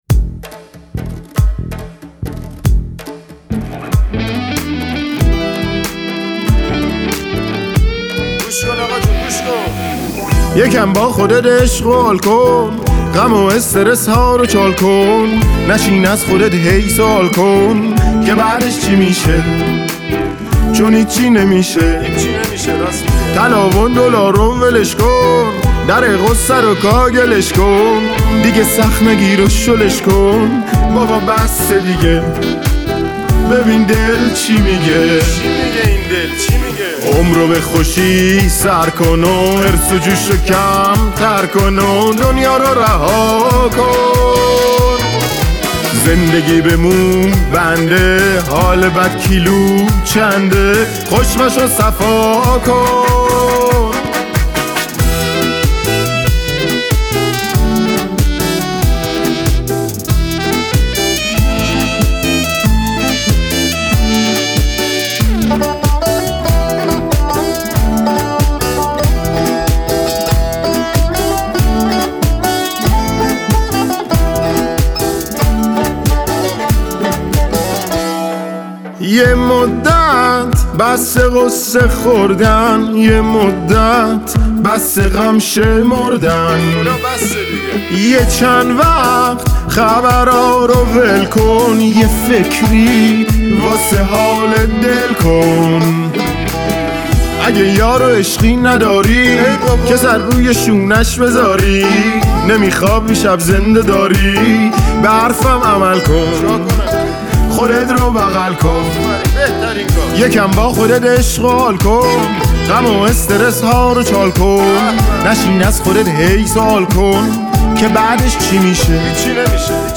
آهنگ بسیار انگیزشی و انرژیک